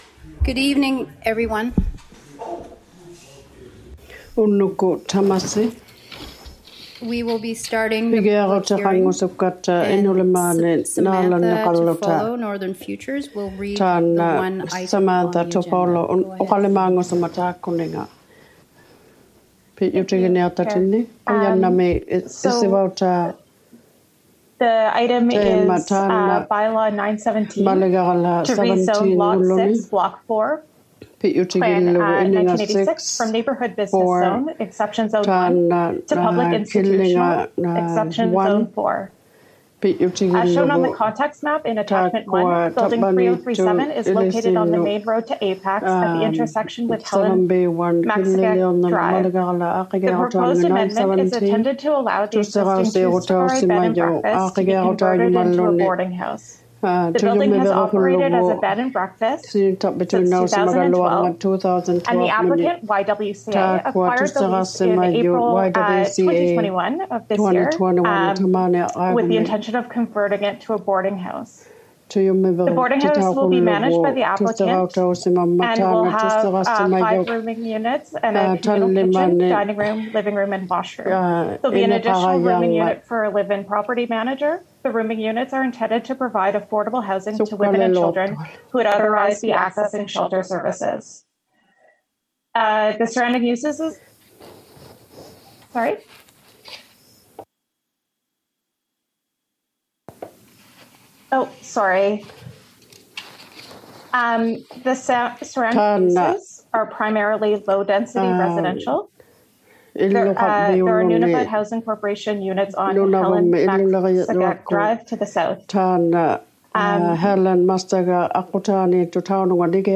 ᓄᓇᓕᐸᐅᔭᒃᑯᑦ ᑲᑎᒪᔨᖏᑕ ᑲᑎᒪᓂᖓᑦ #18 City Council Meeting #18 | City of Iqaluit